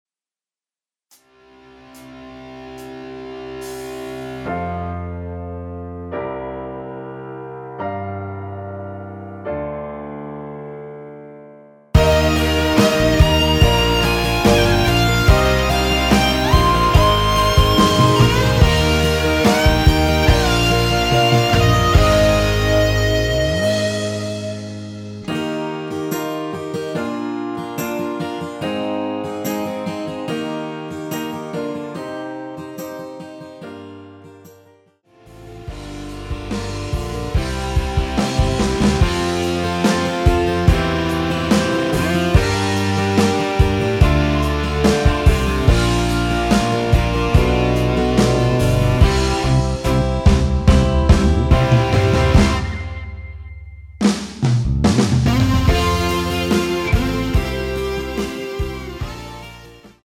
피아노시작 되는 부분 부터 노래 들어가시면 되겠습니다.(미리듣기참조)
Eb
앞부분30초, 뒷부분30초씩 편집해서 올려 드리고 있습니다.
중간에 음이 끈어지고 다시 나오는 이유는